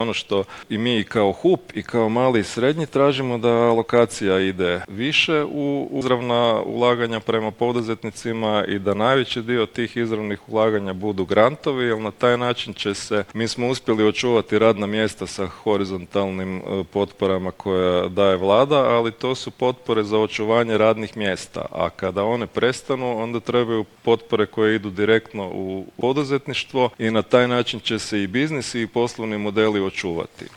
ZAGREB - Ususret donošenju Nacionalnog plana za oporavak i otpornost u organizaciji HUP-a održana je konferencija ''Kakve nas investicije mogu izvući iz krize'' na kojoj su sugovornici koji dolaze iz realnih sektora govorili o preduvjetima potrebnim za oporavak od krize uzrokovane pandemijom koronavirusa, kao i o tome što je domaćoj industriji potrebno da dosegne svoj puni investicijski potencijal.